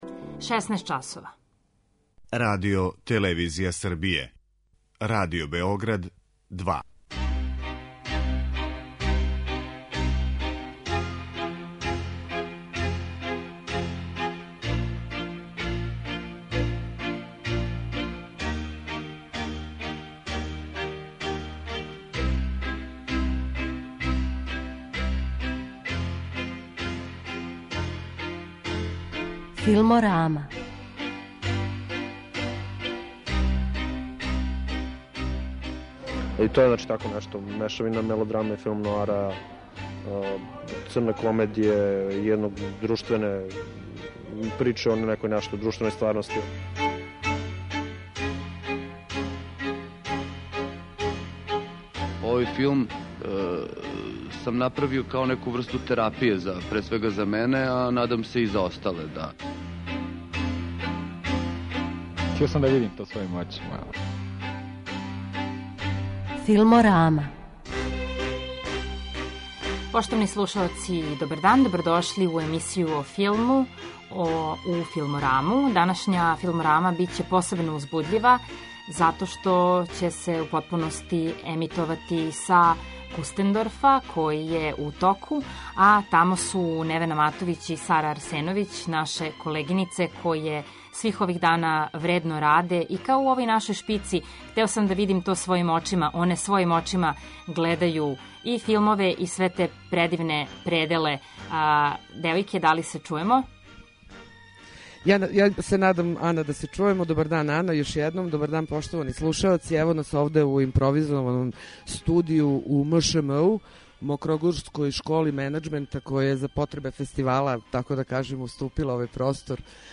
Данашња 'Филморама' емитује се са Мећавника где је у току 12. Међународни филмски и музички фестивал 'Кустендорф'.